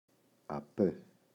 απέ [a’pe]